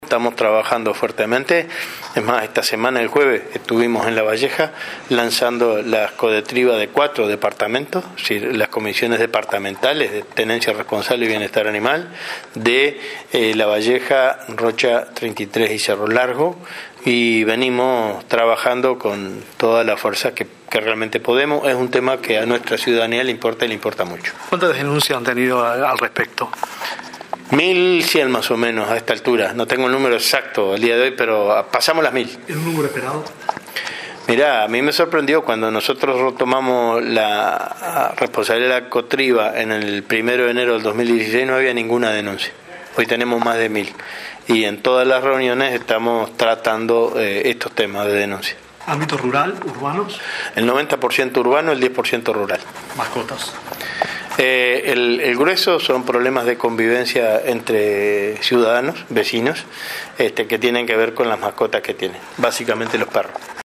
Unas 1.100 denuncias fueron recibidas hasta ahora por la Comisión de Tenencia Responsable y Bienestar Animal, presidida por el Ministerio de Ganadería, Agricultura y Pesca. El 90 % de ellas son problemas de convivencia en zonas urbanas, informó este domingo 23 el subsecretario de la cartera, Enzo Benech, en ocasión de las audiencias previas al Consejo de Ministros abierto del barrio La Teja, en Montevideo.